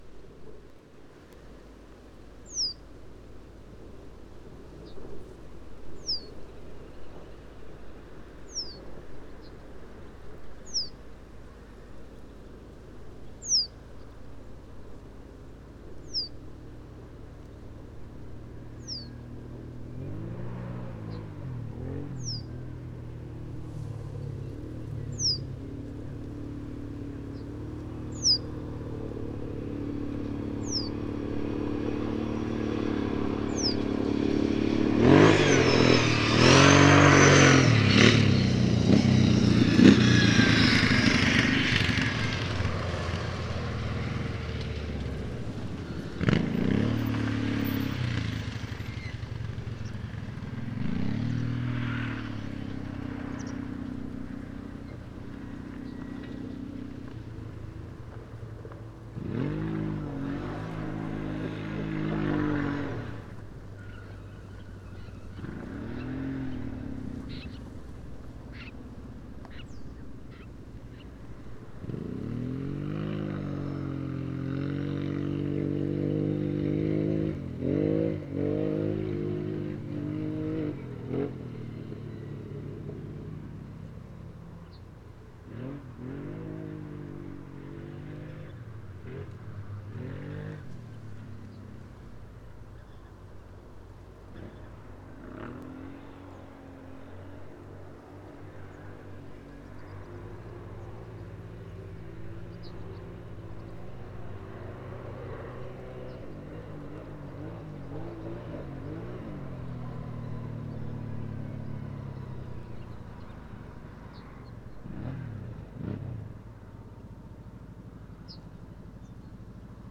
100404, Common Reed Bunting Emberiza schoeniclus, call, Leipzig, Germany
10_reed-bunting.mp3